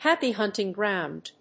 アクセントháppy húnting gròund